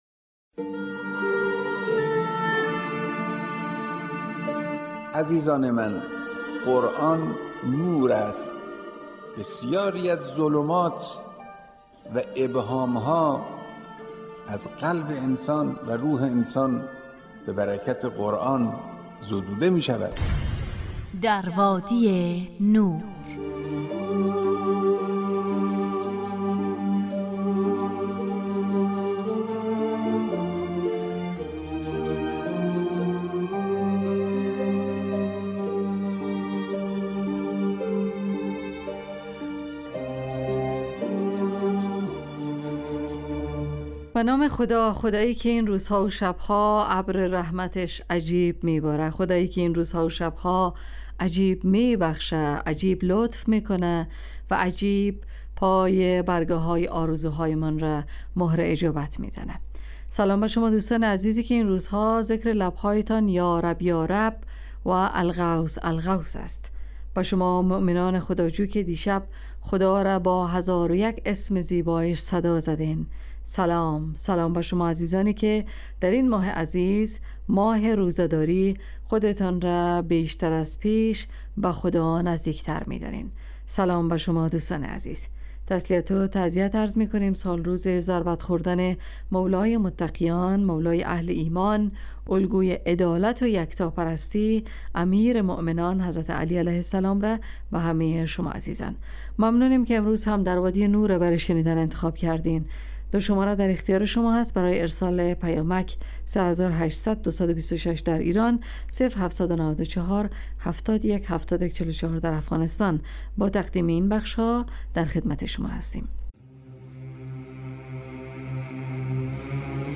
در وادی نور برنامه ای 45 دقیقه ای با موضوعات قرآنی
ایستگاه تلاوت